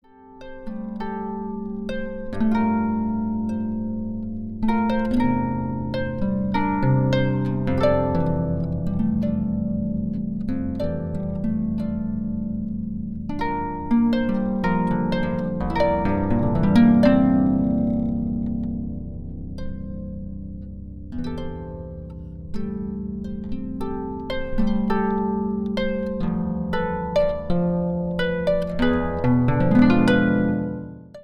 Popular